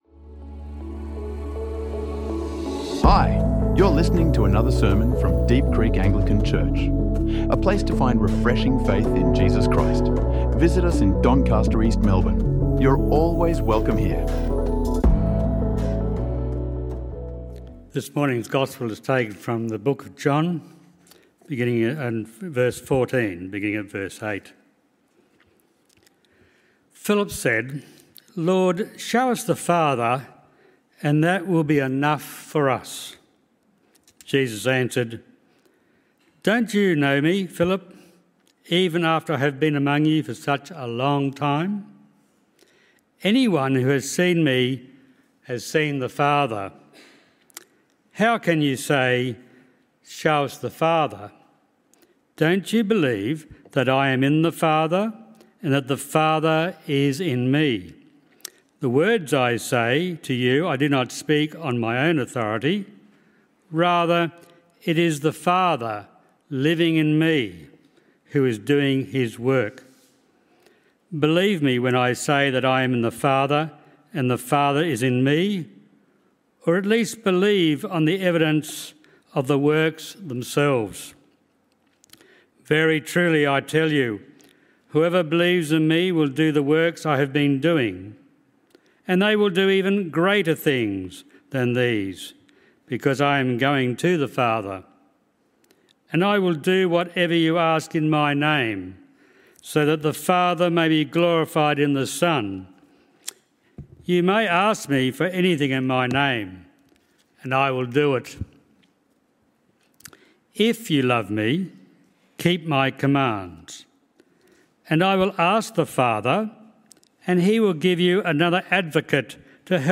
Discover how Jesus steadies troubled hearts in this sermon.